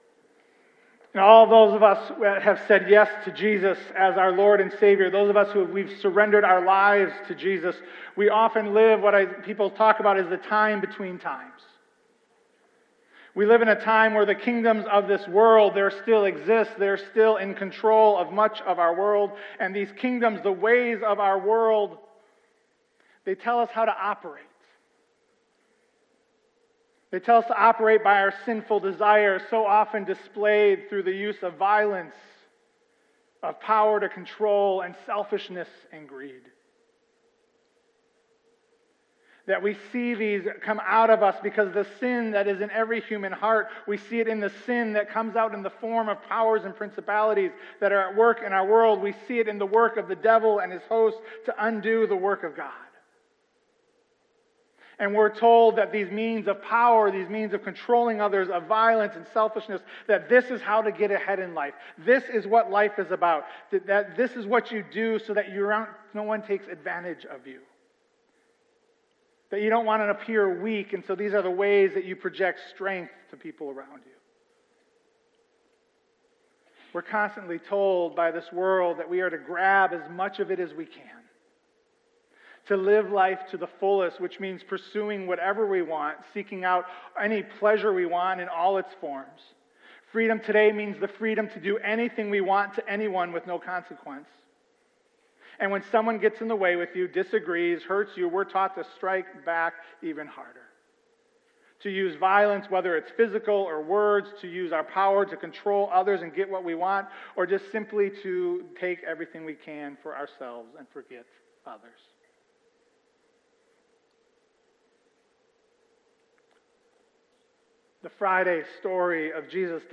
Easter Sunday – Hope Christian Fellowship